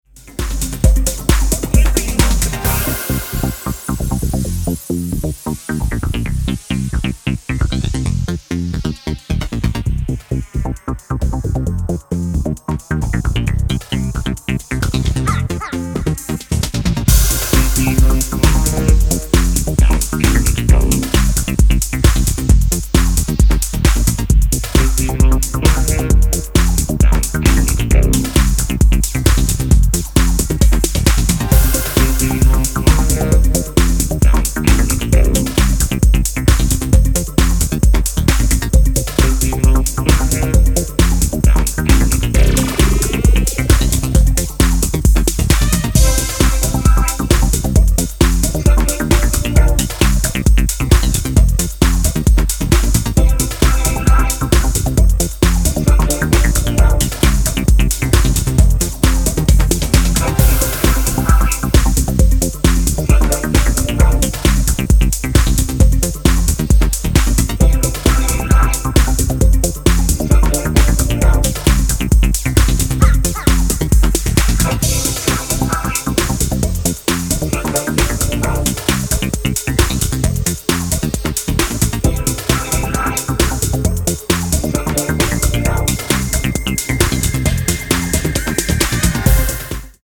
ヴォコーダーヴォイスも気分を盛り上げる80’s エレクトロ感覚濃厚な